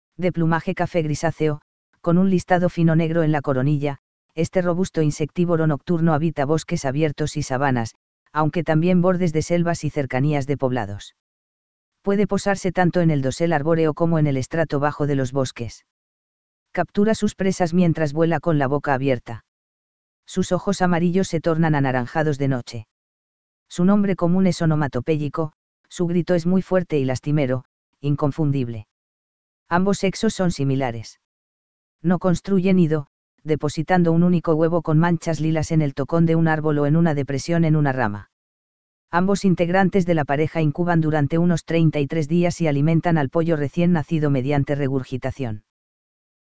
Su nombre común es onomatopéyico; su grito es muy fuerte y lastimero, inconfundible.
Urutau.mp3